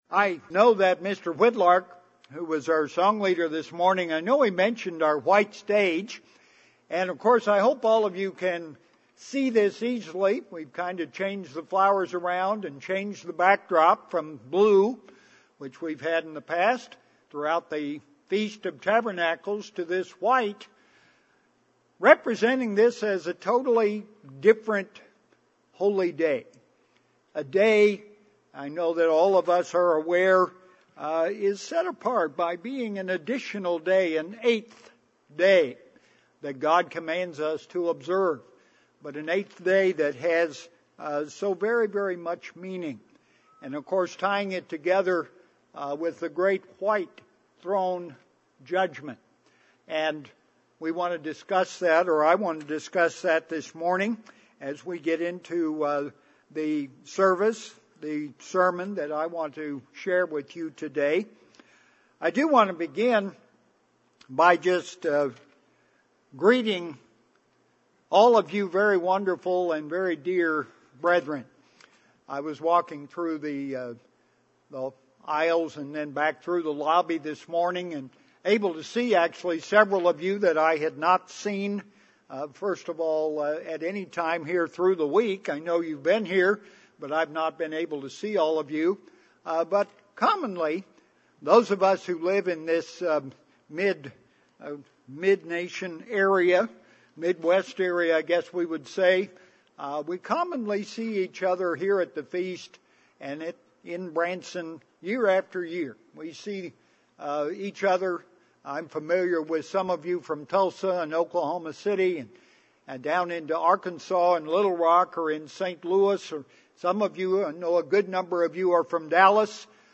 This sermon was given at the Branson, Missouri 2020 Feast site.